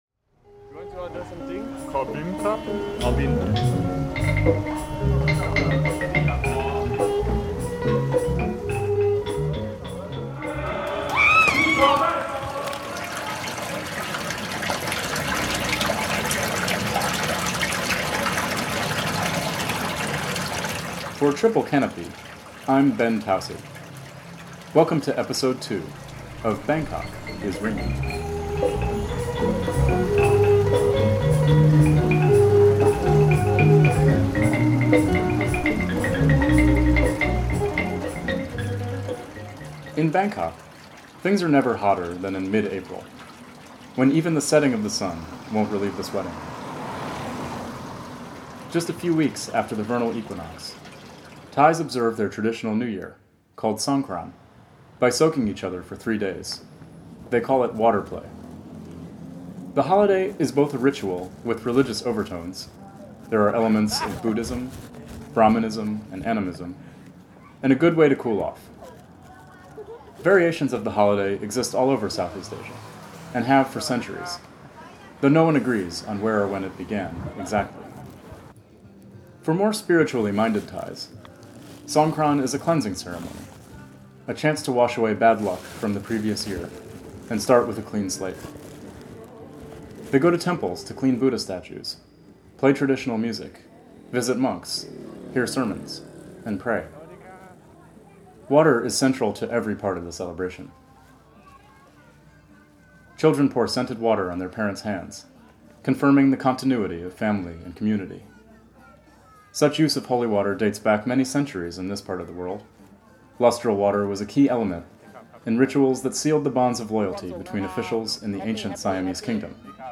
Published on July 6, 2010 Download -:-- / -:-- A series exploring the politics of urban sound in Bangkok and beyond, through first-person reporting, field recordings, and analysis.